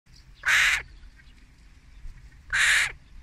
02-varju.mp3